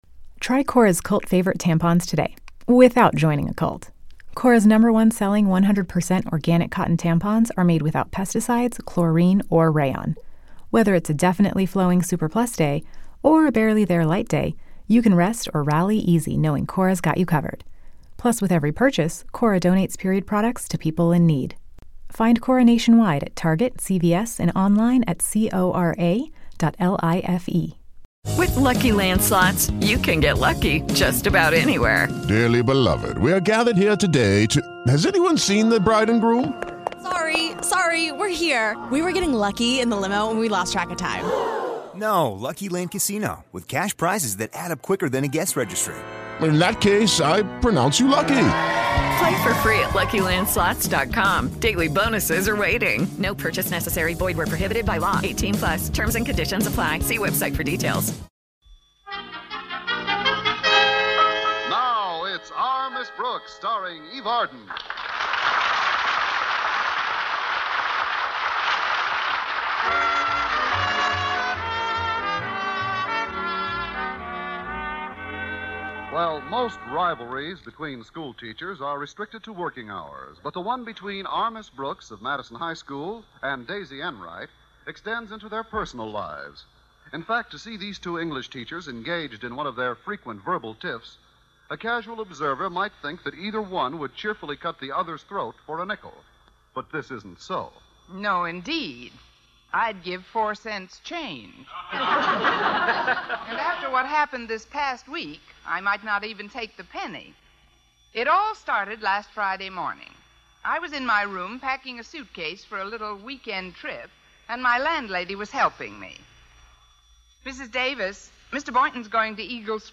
Our Miss Brooks was a beloved American sitcom that ran on CBS radio from 1948 to 1957.
The show starred the iconic Eve Arden as Connie Brooks, a wisecracking and sarcastic English teacher at Madison High School. Arden's portrayal of Miss Brooks was both hilarious and endearing, and she won over audiences with her quick wit and sharp one-liners The supporting cast of Our Miss Brooks was equally memorable. Gale Gordon played the uptight and pompous Principal Osgood Conklin, Richard Crenna played the dimwitted but lovable student Walter Denton, and Jane Morgan played Miss Brooks' scatterbrained landlady, Mrs. Davis.